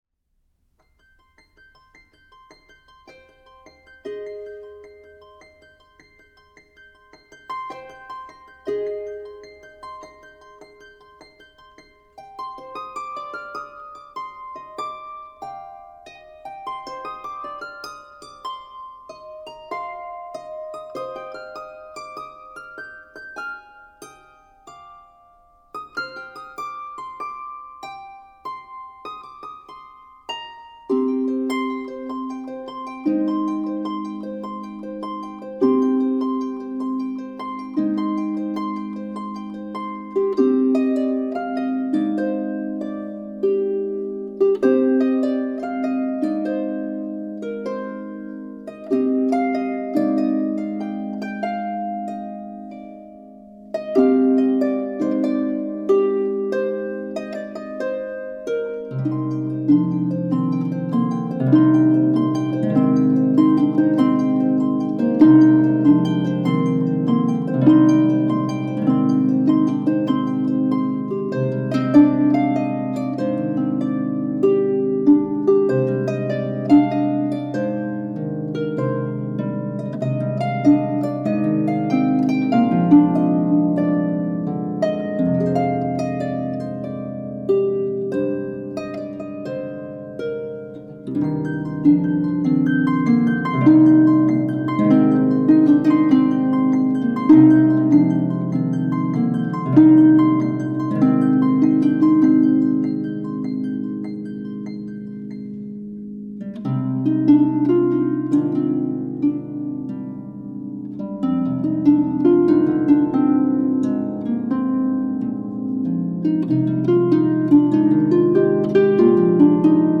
traditional Christmas carol
for solo lever or pedal harp